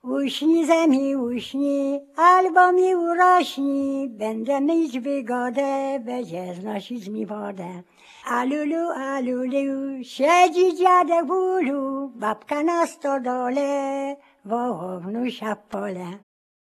KOŁYSANKI LULLABIES
The lullabies, children's songs, counting-out games and children's play contained on this CD come from the Polish Radio collection.
Half of the recorded tracks are lullabies (tracks 1-30), mostly in recordings from the 1970s, 1980s and 1990s, performed by singers born in the early 20th century.[...]